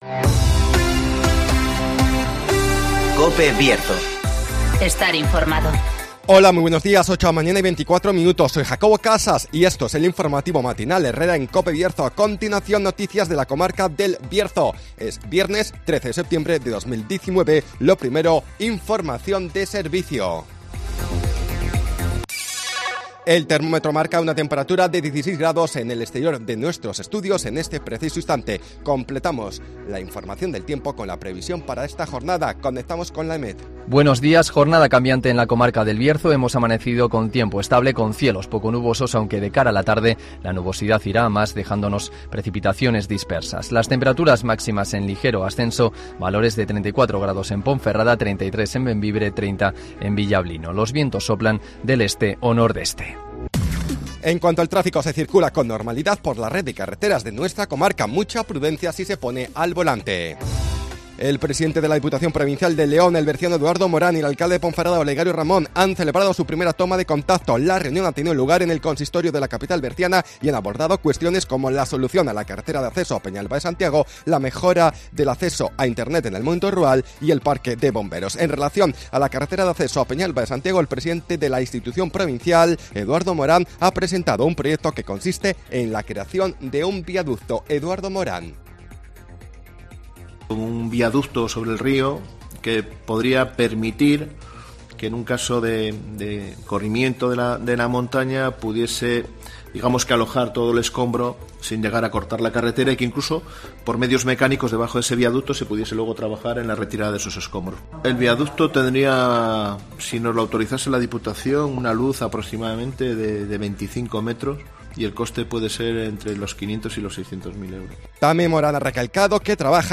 INFORMATIVOS
-Conocemos las noticias de las últimas horas de nuestra comarca, con las voces de los protagonistas